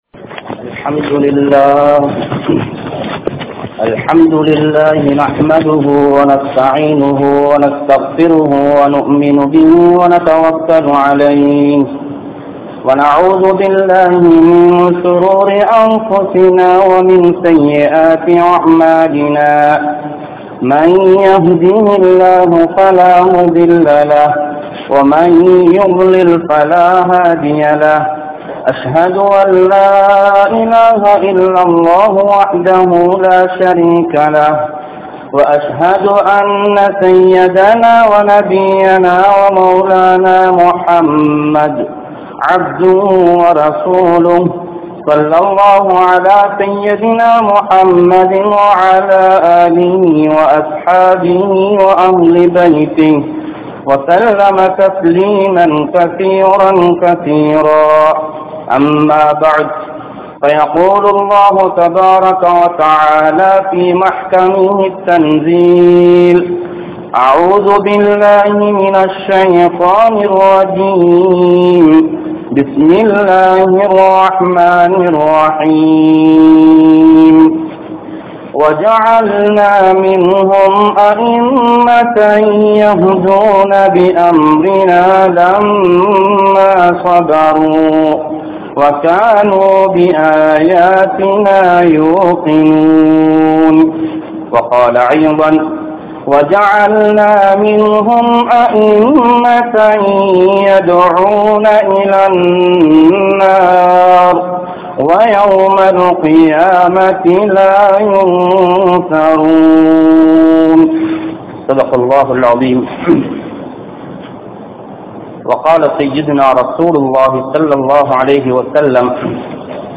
Nabi(SAW)Avarhal Anupa Pattathan Noakkam (நபி(ஸல்)அவர்கள் அனுப்பப்பட்டதன் நோக்கம்) | Audio Bayans | All Ceylon Muslim Youth Community | Addalaichenai
Hudha Jumua Masjidh